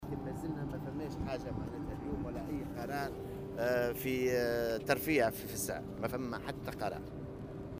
Augmentation des tarifs du transport public: Le ministre du transport explique